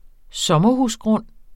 Udtale [ ˈsʌmʌhus- ]